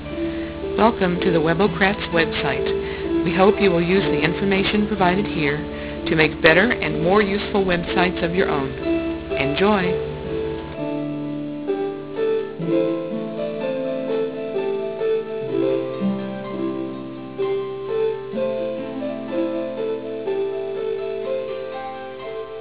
[A lower resolution welcoming wave file] The same welcome message, but this time sampled at about the same level as telephone quality.